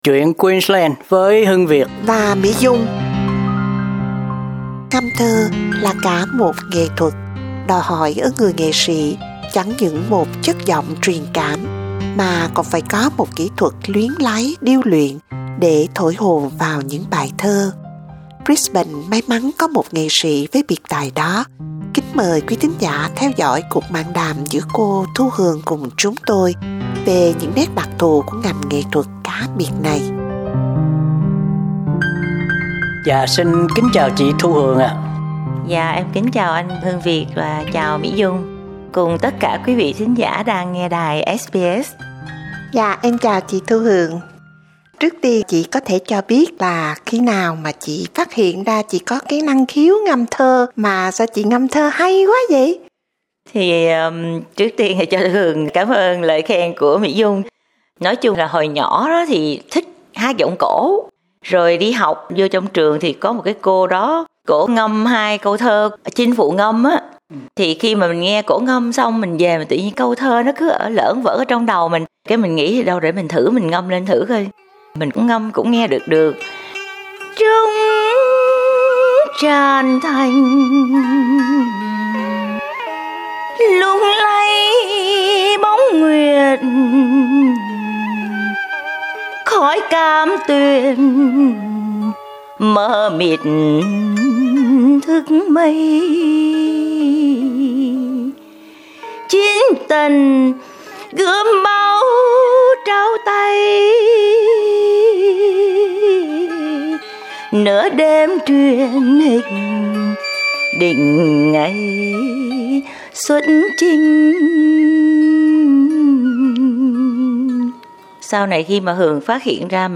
Ngâm thơ là cả một nghệ thuật, đòi hỏi ở người nghệ sĩ chẳng những một chất giọng truyền cảm mà còn phải có một kỹ thuật luyến láy điêu luyện để thổi hồn vào những bài thơ.